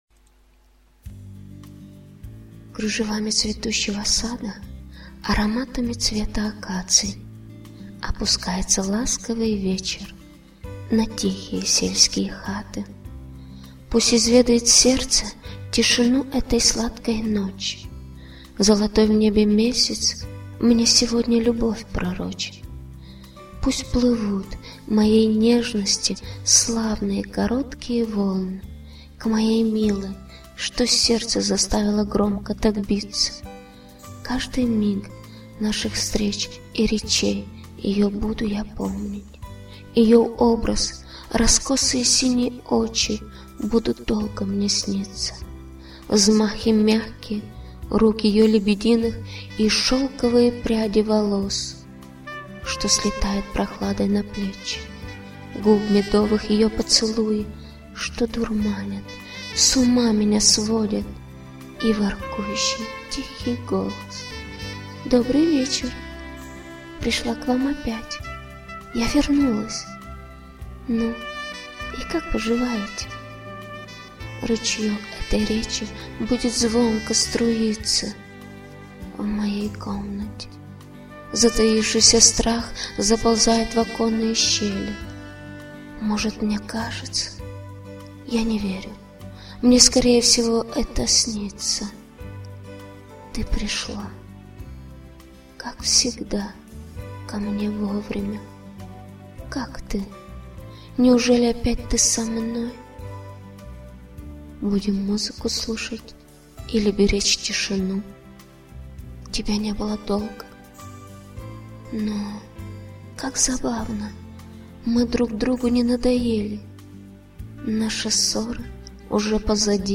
ВИД ТВОРУ: Вірш
12 12 12 Такой приятный голос, прекрасное чтение!